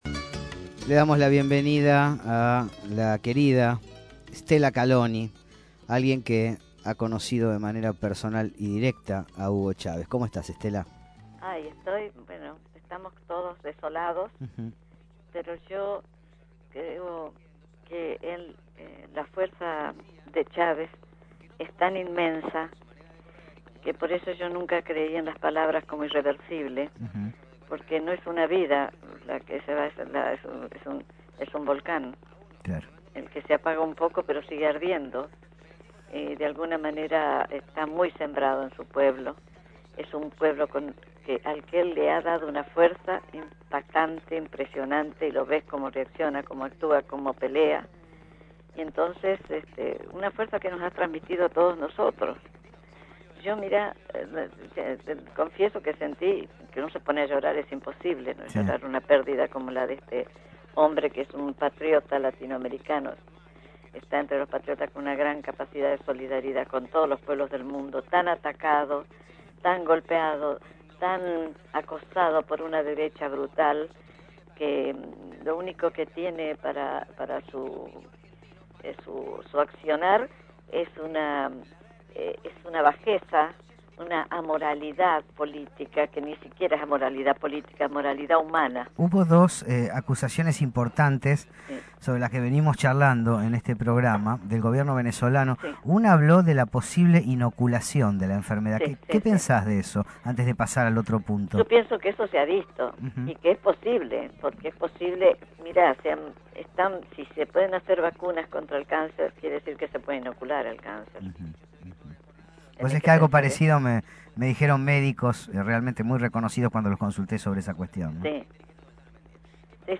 Stella Calloni dijo en La Señal acerca del deceso del Comandante Hugo Chávez: “la fuerza de este hombre hace que no sea una vida lo que se perdió, sino un volcán que se apagó un poco pero sigue ardiendo”.